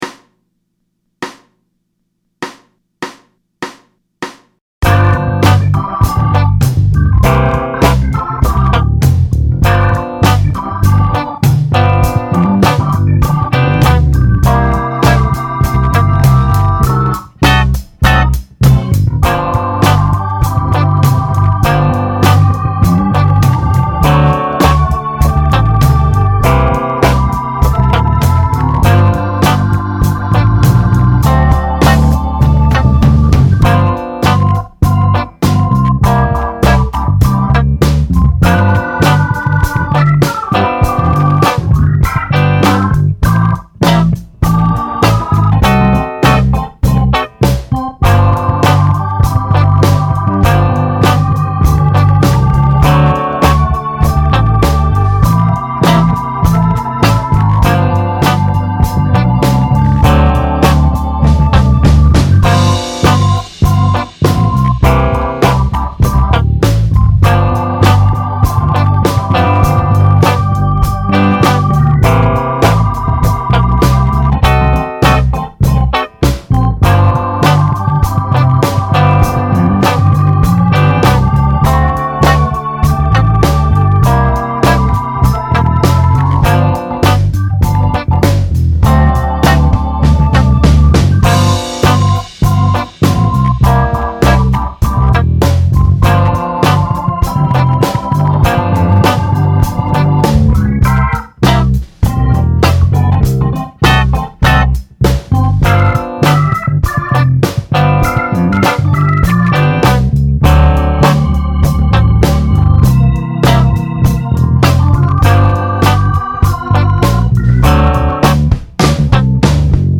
Blues Funk Jam Workout